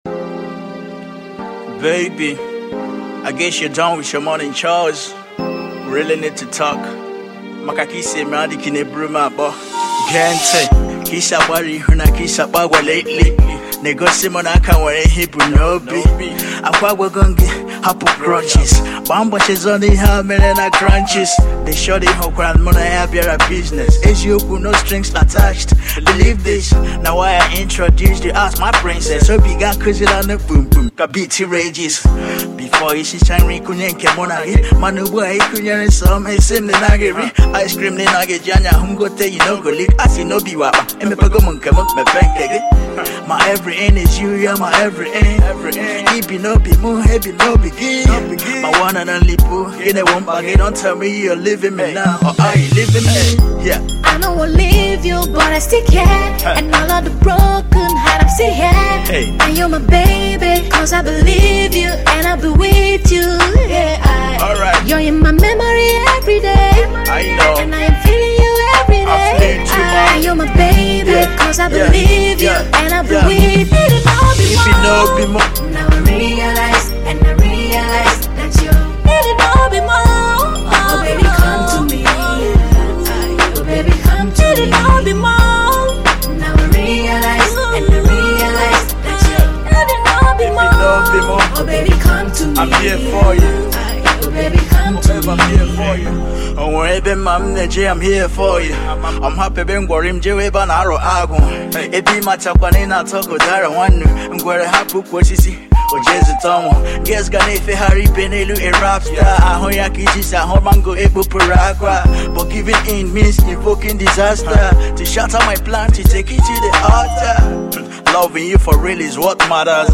indigenous Hip-Hop
Indigenous Igbo Rapper